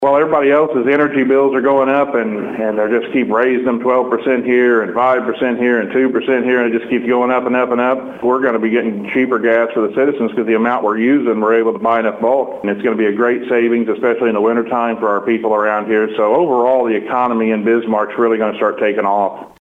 Bismarck Mayor, Seth Radford, says it's going to bring lower energy prices to residents and help new business coming to town.